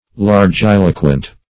Largiloquent \Lar*gil"o*quent\